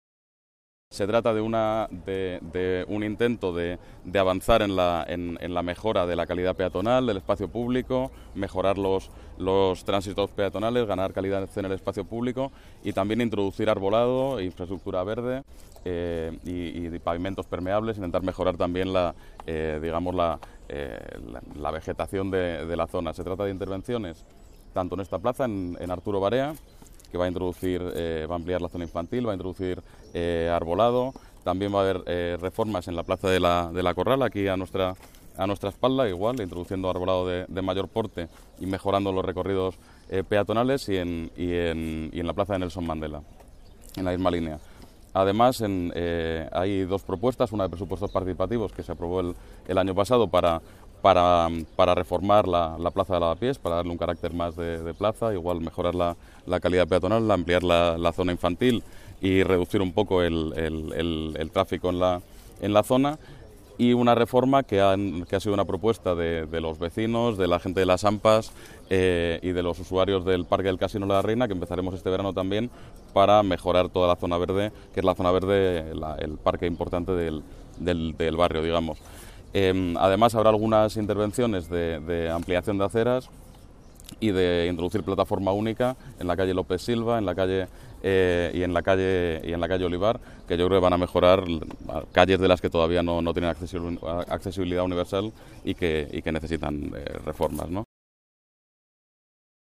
García Castaño presenta el Plan de Renaturalización de Lavapiés